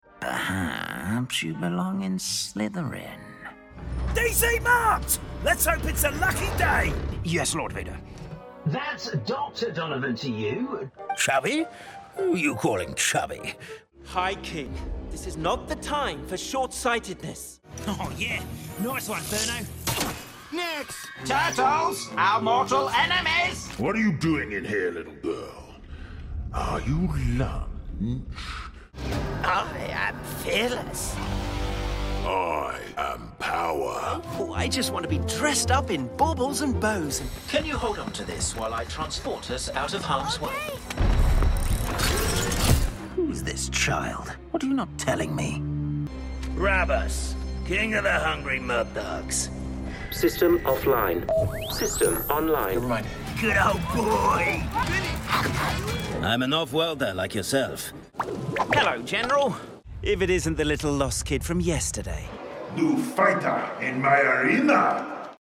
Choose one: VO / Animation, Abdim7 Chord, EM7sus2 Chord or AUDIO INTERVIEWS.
VO / Animation